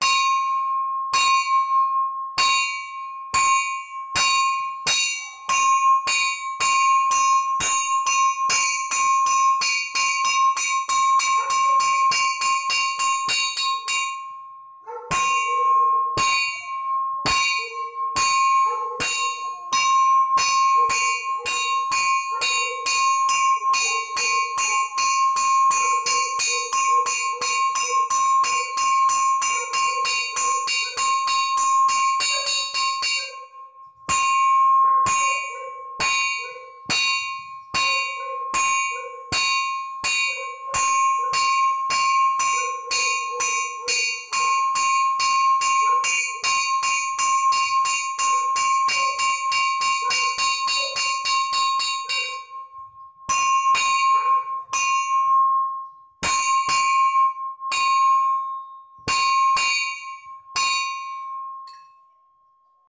Nhạc Chuông Tiếng Kẻng Báo Thức Trong Quân Đội